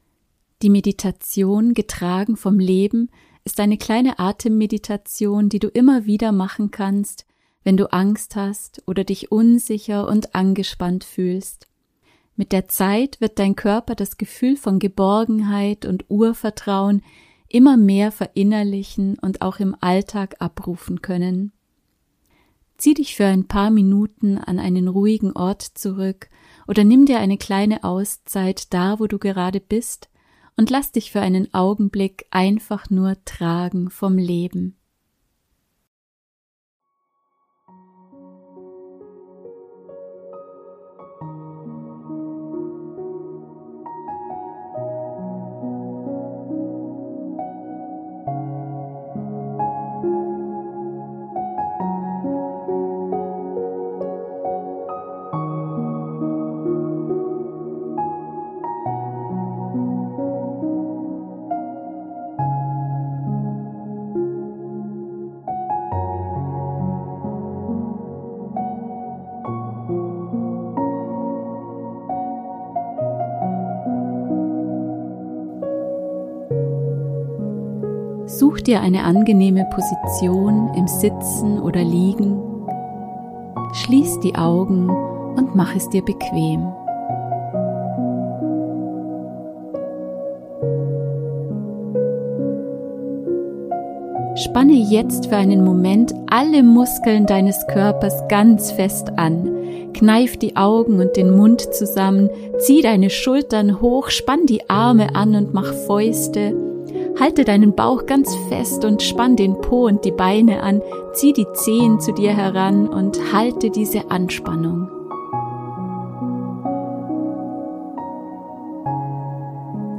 #4 Meditation: "Getragen vom Leben" ~ Geistperlen Podcast